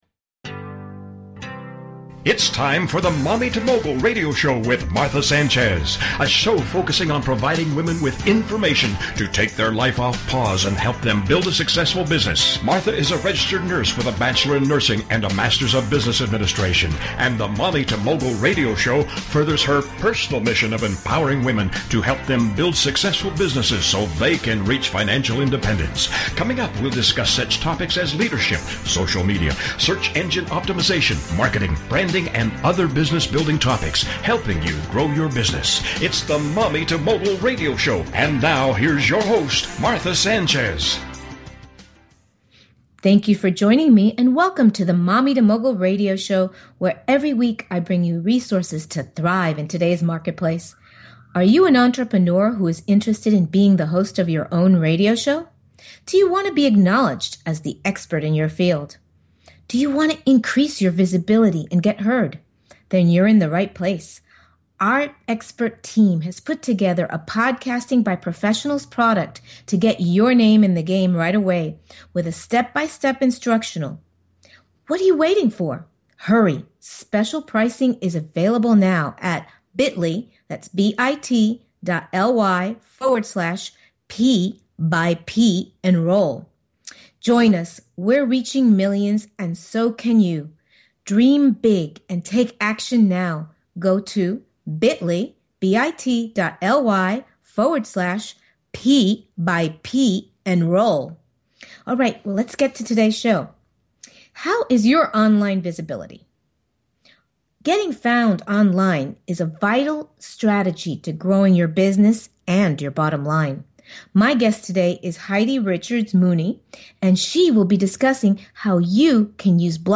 Radio/PODCAST Interviews